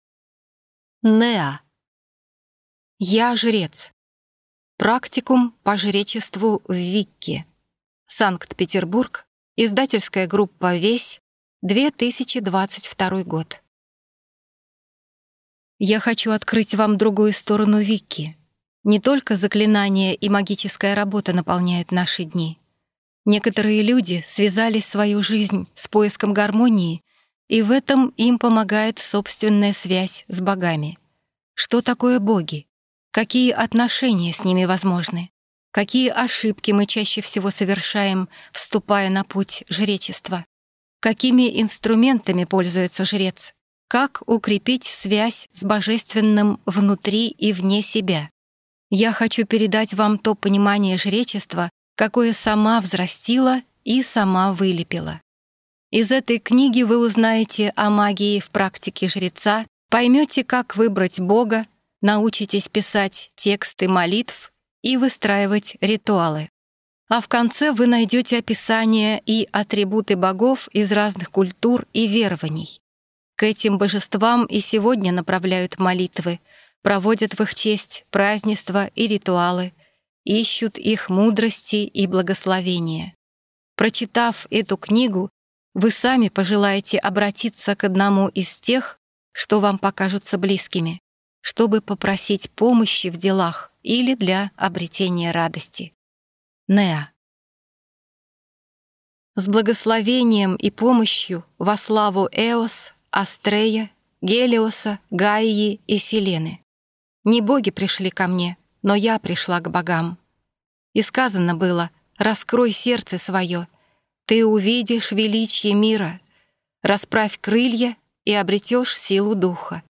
Аудиокнига Я жрец. Практикум по жречеству в Викке | Библиотека аудиокниг